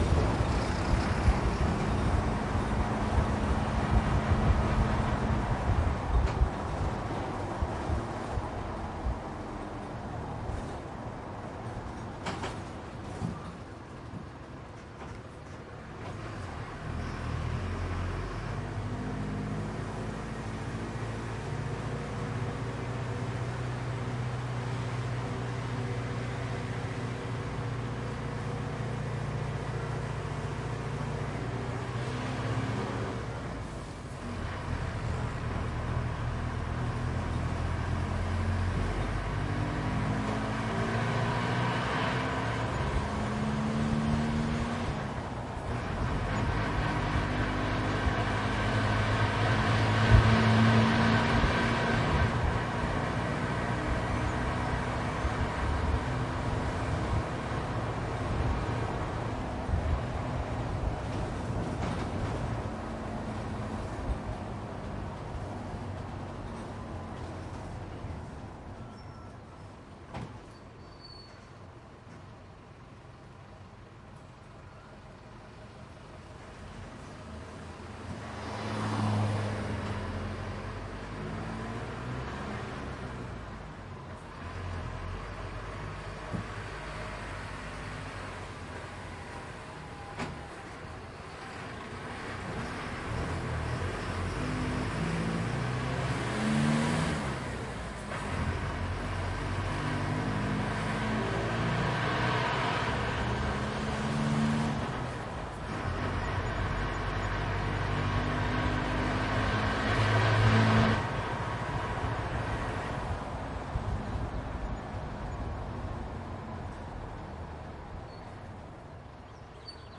巴士发动机002
描述：sennheiser mkh 416声音设备744t
标签： 公交车 发动机
声道立体声